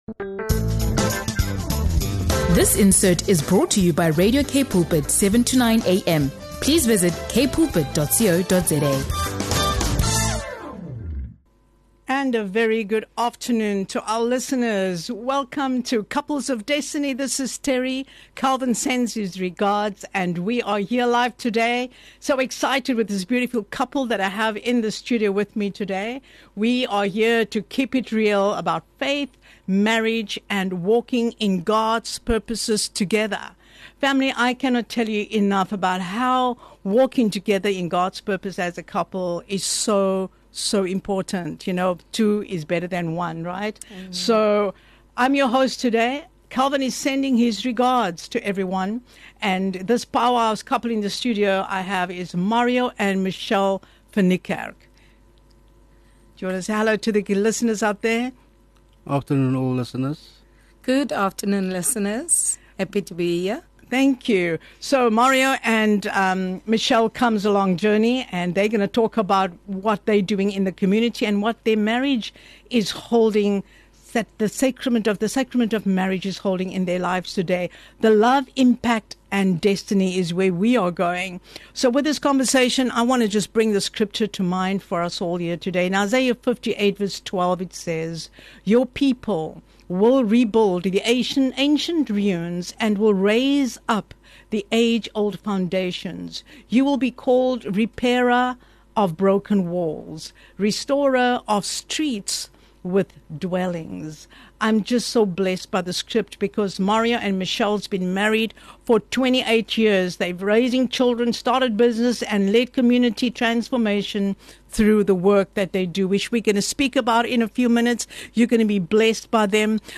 Don’t miss this inspiring conversation about love, purpose, and impact.